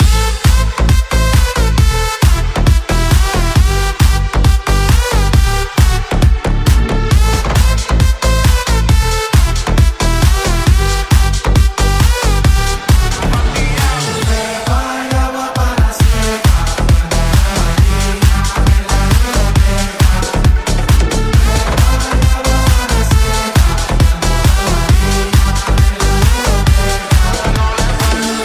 Genere: house,deep,edm,remix,hit